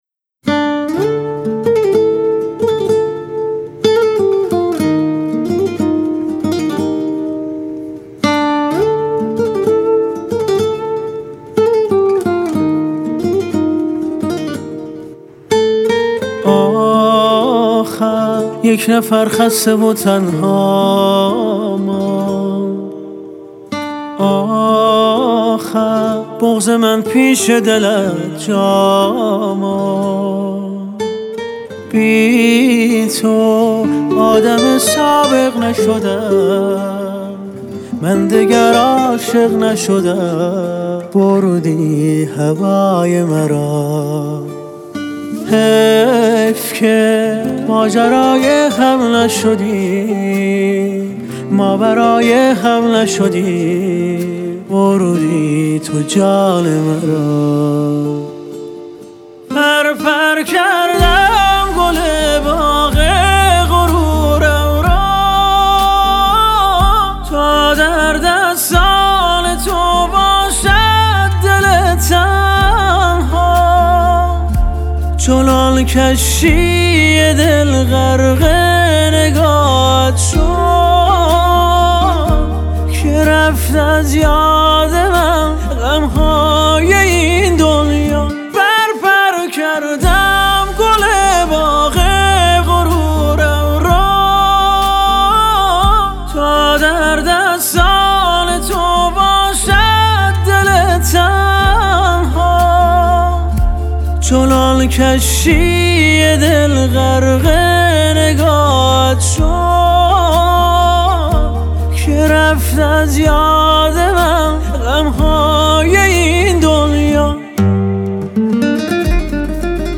اسلو ورژن